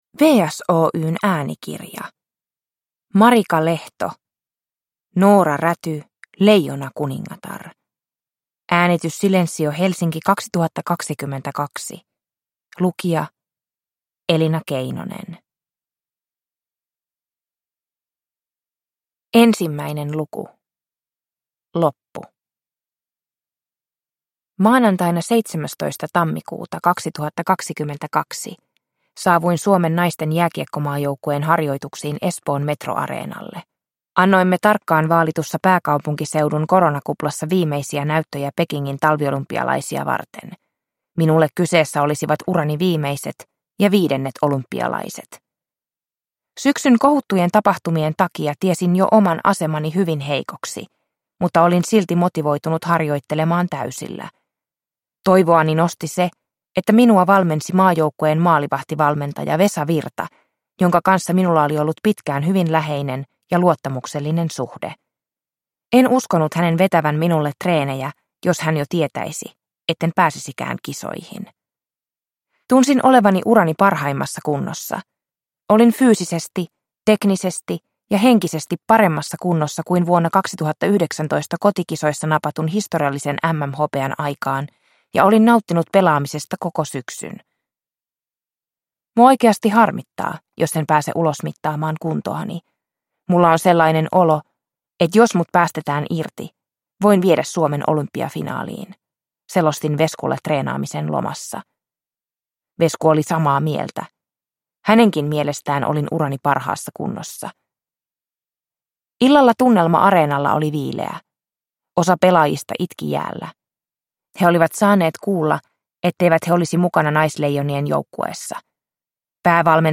Noora Räty - Leijonakuningatar – Ljudbok – Laddas ner